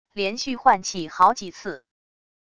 连续换气好几次wav音频